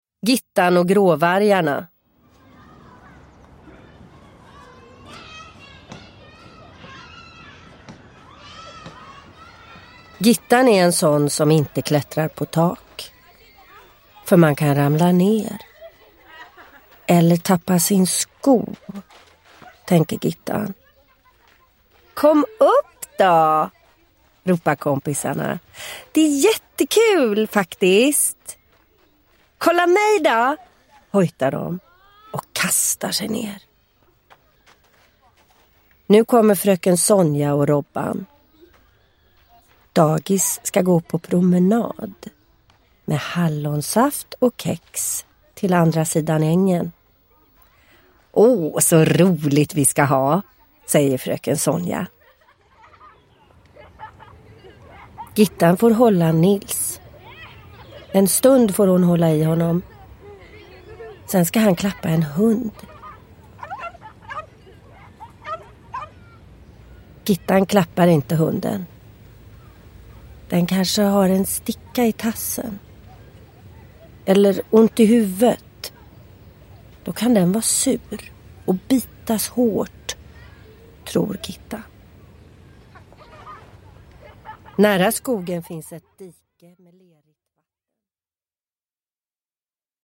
Gittan och gråvargarna – Ljudbok – Laddas ner